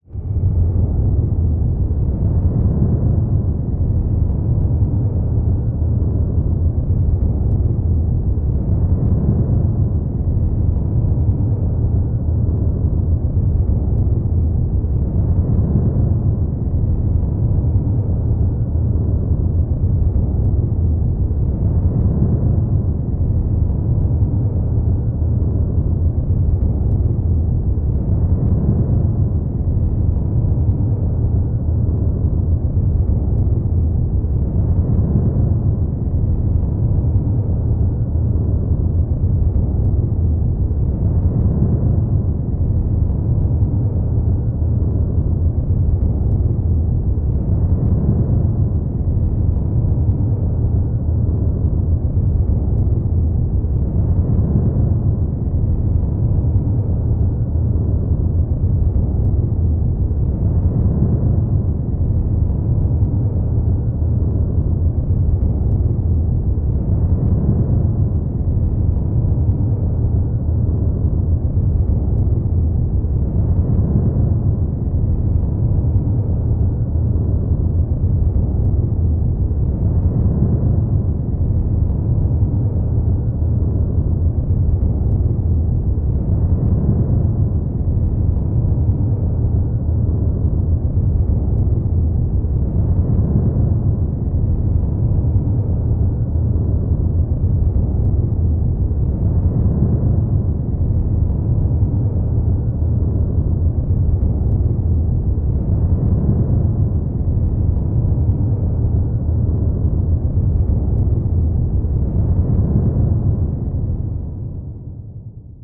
free horror ambience 2
ha-pressure-nofx.wav